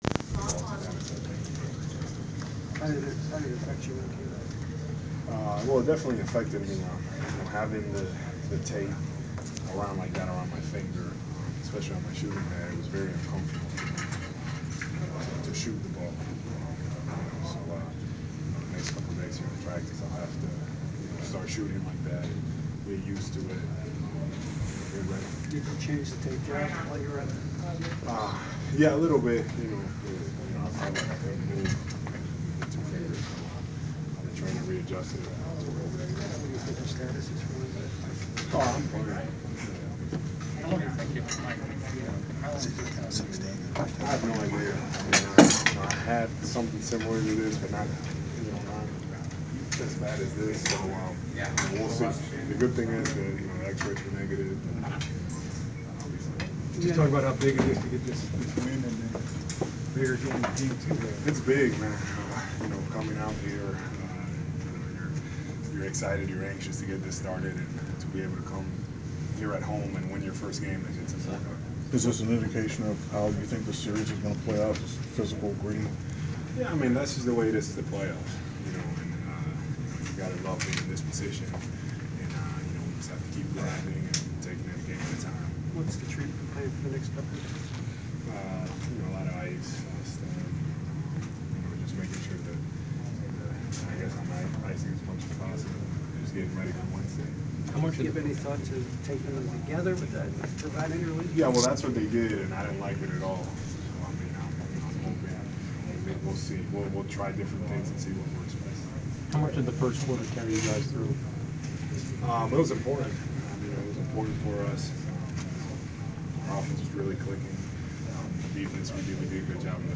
Inside the Inquirer: Postgame presser with Atlanta Hawks’ Al Horford (4/19/15)
We attended the postgame presser of Atlanta Hawks’ center Al Horford following his team’s 99-92 Game One win over the Brooklyn Nets in the first round of the Eastern Conference Playoffs.